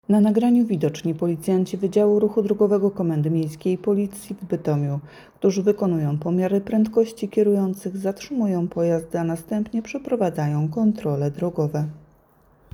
Nagranie audio Audiodeskrypcja